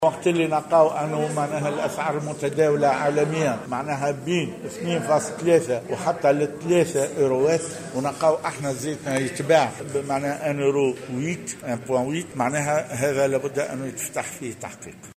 وأوضح الزار في تصريح اليوم لمراسل "الجوهرة أف أم" على هامش انعقاد لجنة الفلاحة والأمن الغذائي، أنه من غير المعقول أن يتم بيع زيت الزيتون المحلي بأسعار أقل بكثير من الأسعار المتداولة، وفق قوله.